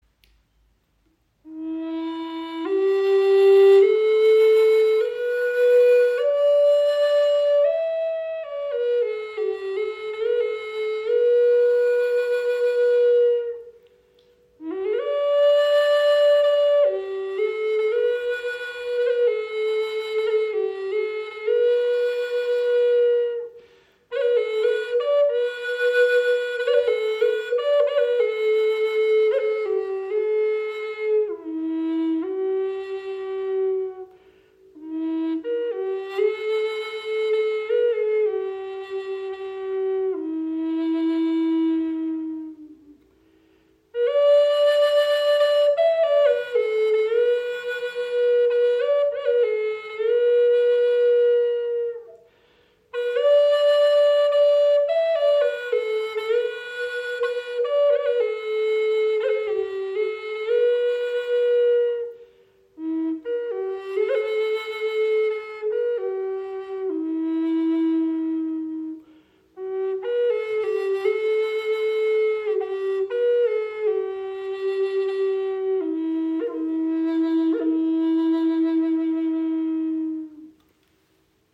Gebetsflöte in tiefem E - 432 Hz
Sie schenkt Dir ein wundervolles Fibrato, kann als Soloinstrument gespielt werden oder als weiche Untermahlung Deiner Musik.